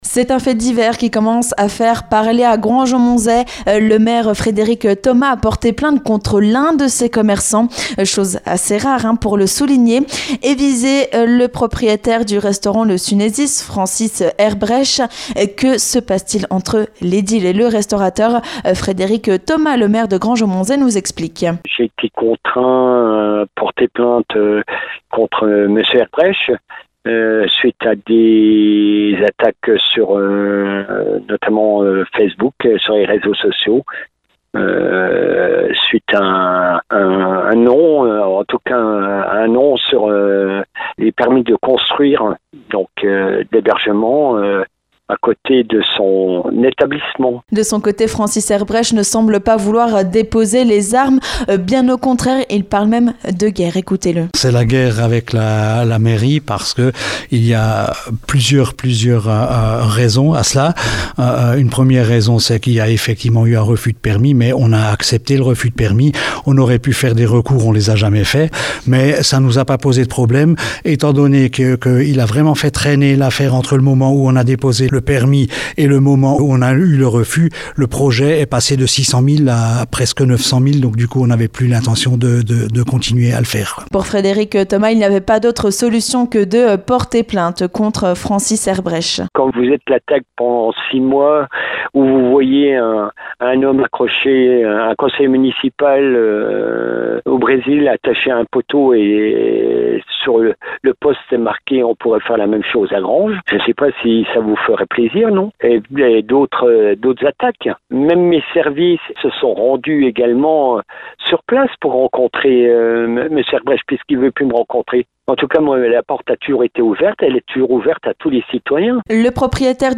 Les deux hommes s'expliquent au micro de Vosges FM.